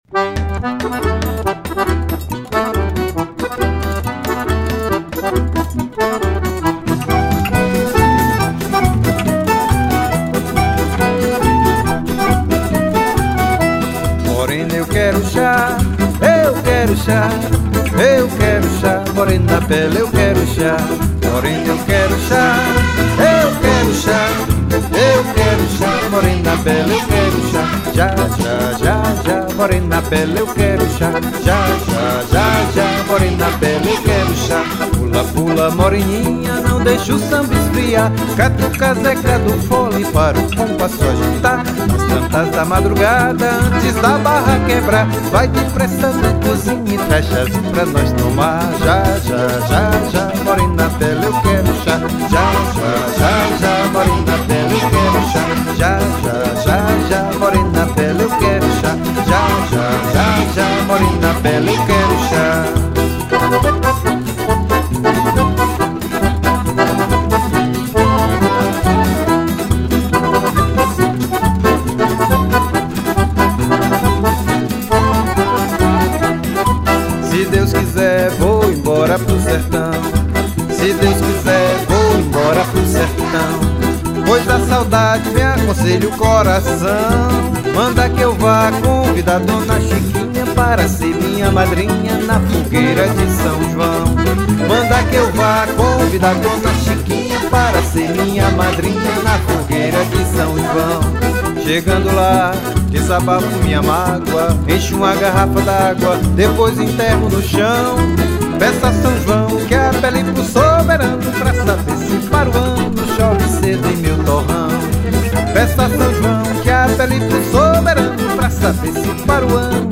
1434   03:54:00   Faixa:     Forró
Acoordeon, Voz
Guitarra
Flauta
Percussão